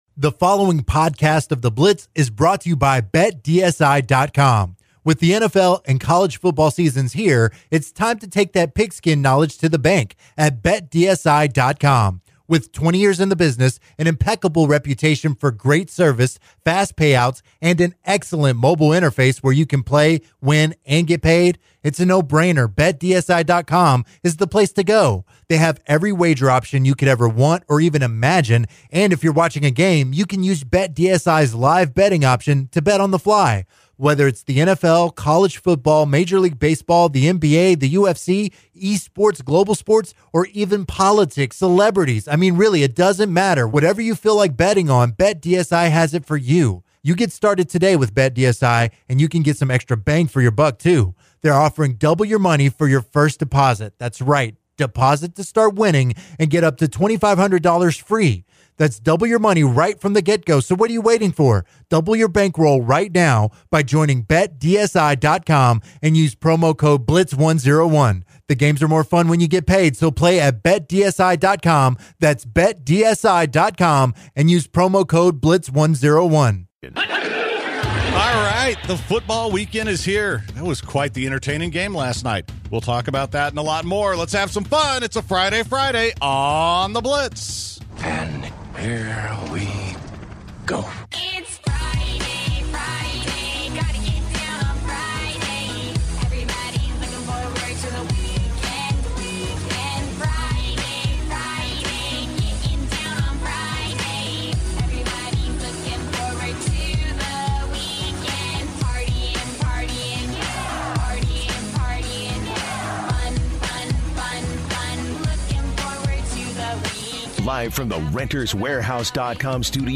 The guys answer some Texans questions from callers and the text line and delve into the Texans depth problems and future.